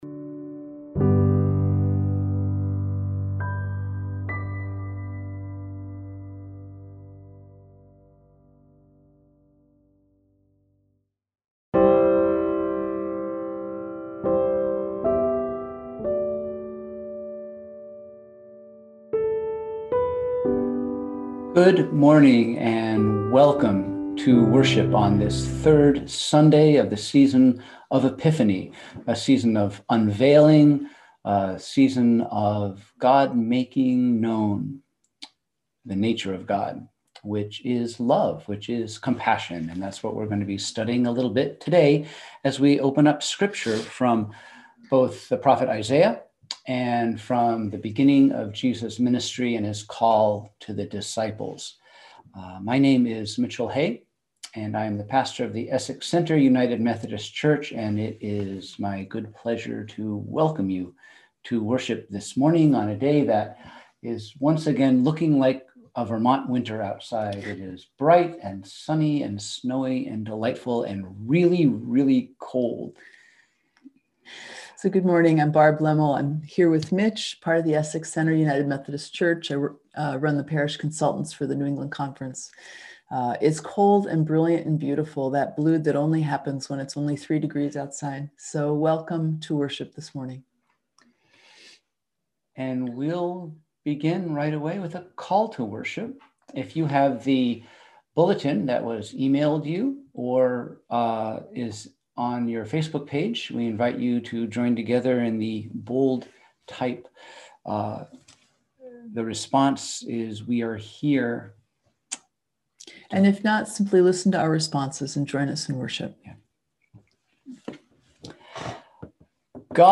We held virtual worship on Sunday, January 24, 2021 at 10:00am!
Sermons Where Does It Hurt?